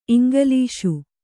♪ iŋgalīṣu